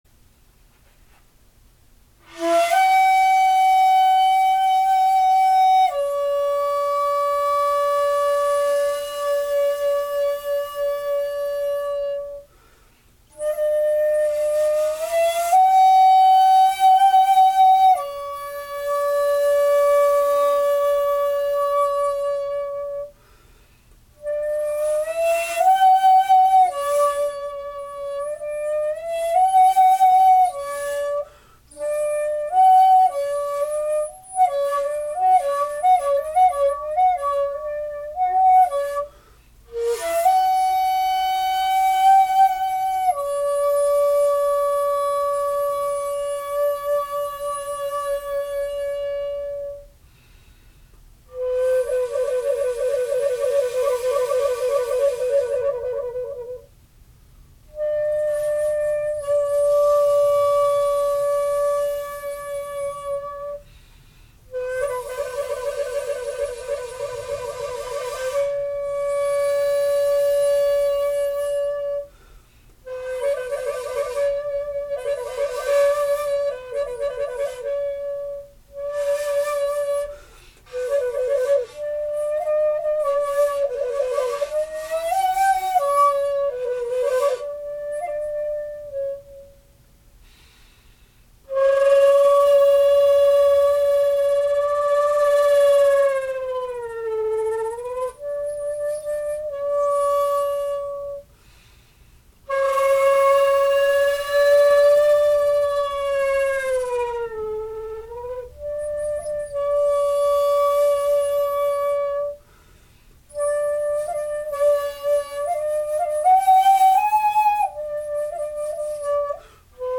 ピッチピッチ（音程）と喧しく叫ばれる前の時代ですので現代管よりは音が低めですが、その分、落ち着いた響きです。
録音はしていますが、所詮機械ですので生の音にはほど遠いことをご留意ください。
－＞特殊音符、コロなども美しく処理できます。